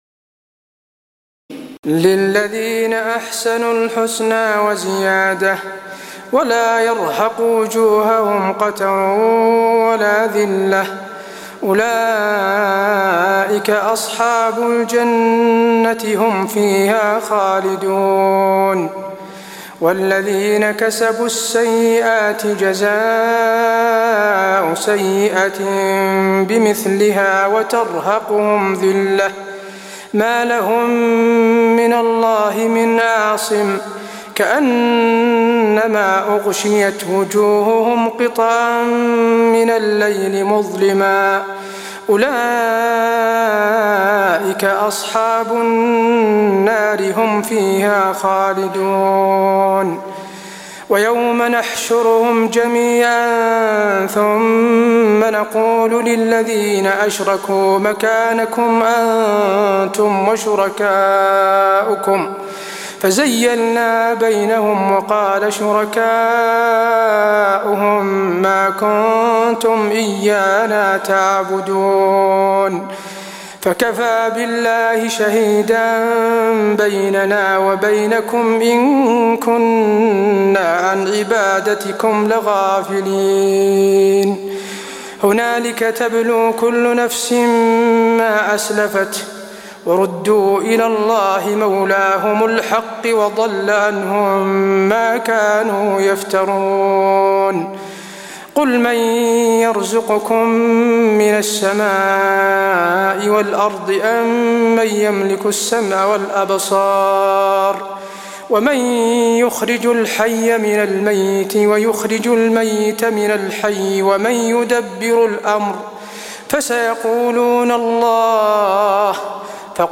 تراويح الليلة العاشرة رمضان 1423هـ من سورة يونس (26-70) Taraweeh 10 st night Ramadan 1423H from Surah Yunus > تراويح الحرم النبوي عام 1423 🕌 > التراويح - تلاوات الحرمين